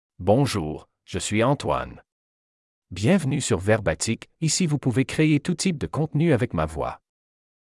MaleFrench (Canada)
Antoine is a male AI voice for French (Canada).
Voice sample
Male
Antoine delivers clear pronunciation with authentic Canada French intonation, making your content sound professionally produced.